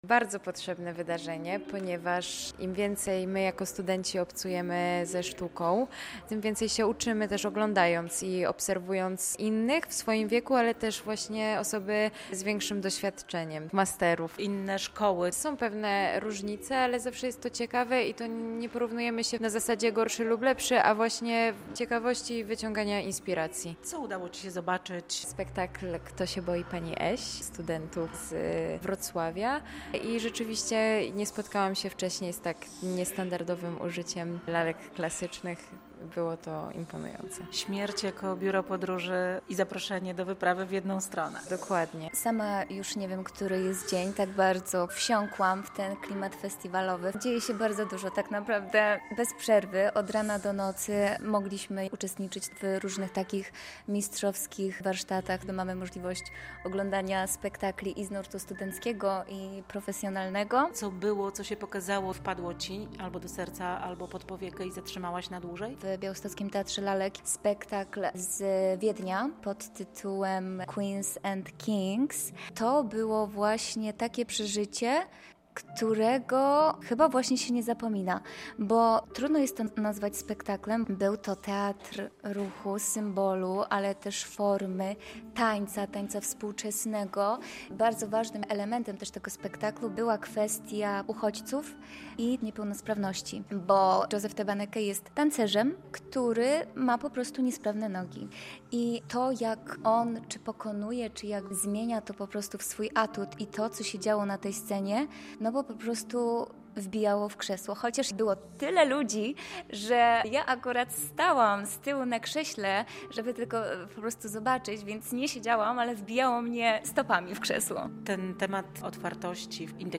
11. Międzynarodowy Festiwal Szkół Lalkarskich LALKANIELALKA - relacja